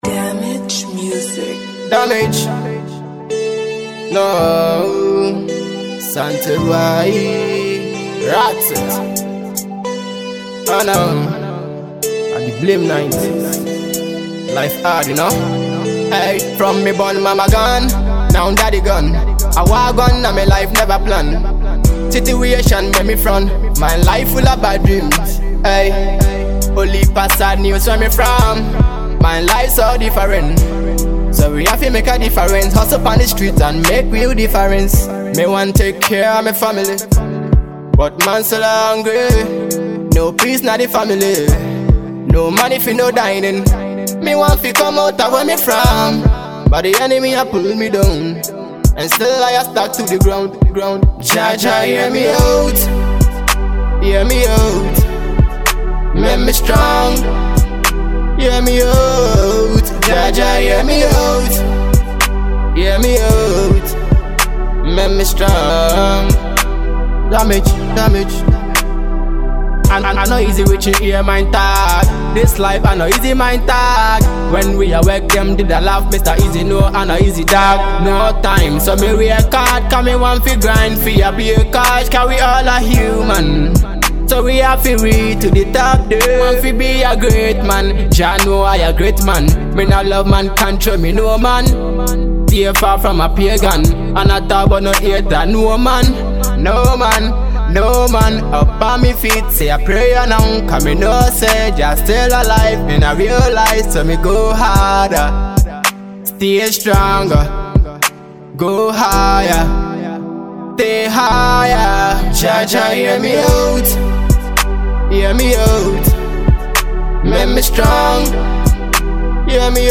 GHANA MUSIC
very danceable tune